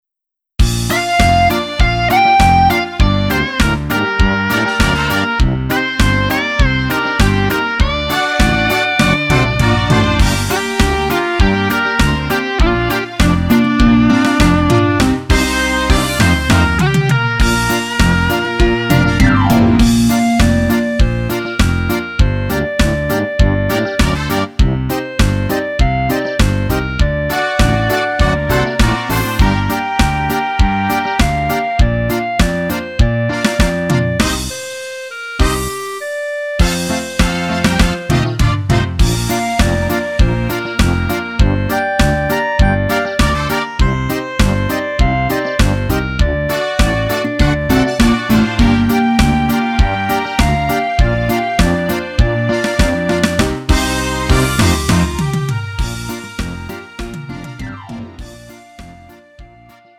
음정 원키 3:03
장르 가요 구분 Lite MR